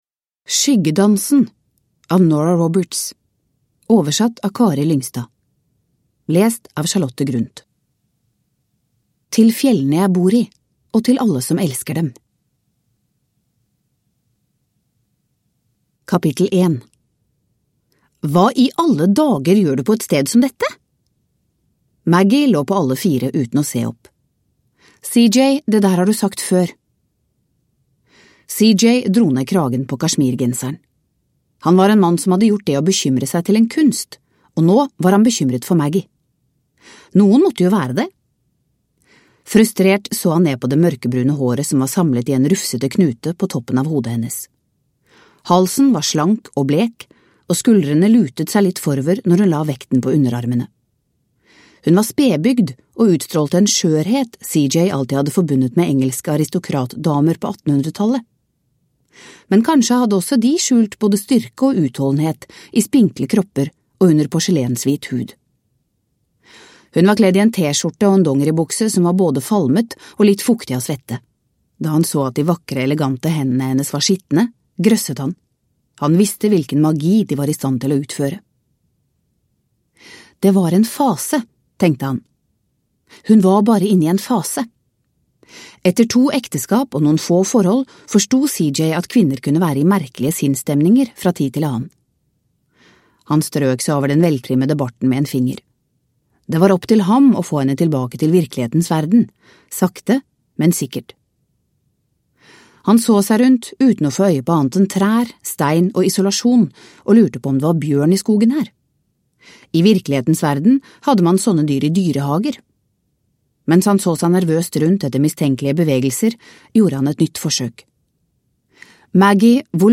Skyggedansen – Ljudbok